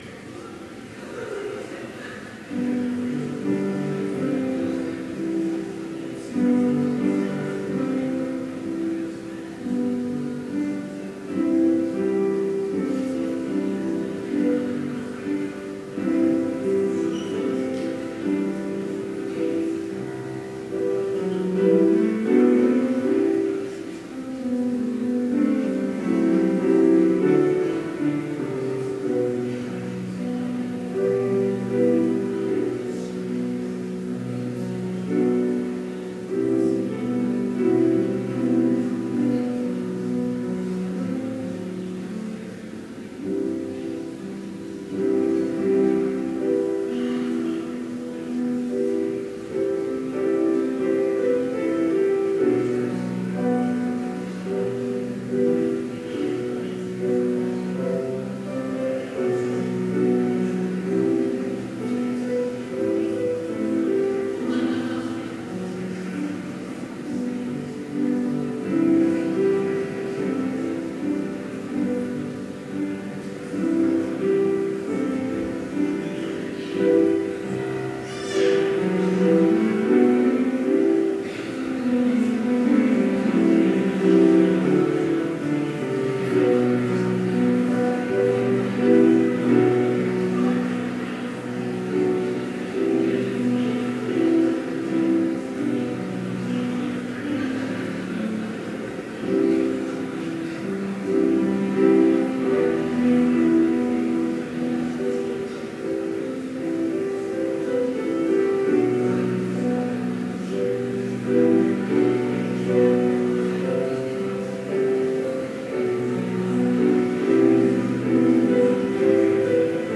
Complete service audio for Chapel - November 1, 2017